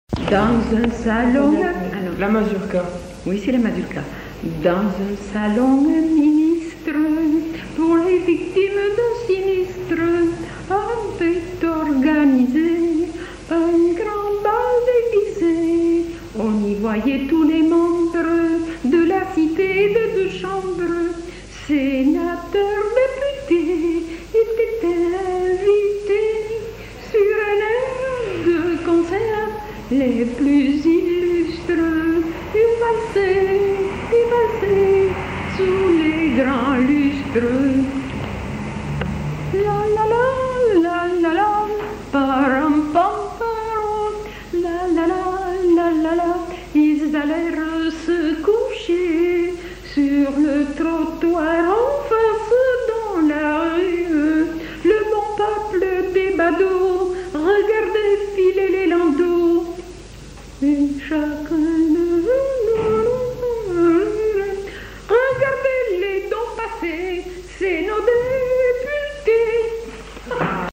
Aire culturelle : Haut-Agenais
Genre : chant
Effectif : 1
Type de voix : voix de femme
Production du son : chanté ; fredonné
Danse : mazurka